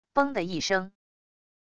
绷的一声wav音频